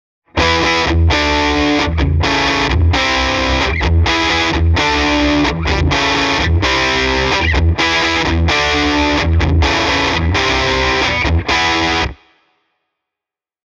LES PAUL SÄRÖLLÄ
the-toob-j-e28093-les-paul-overdrive.mp3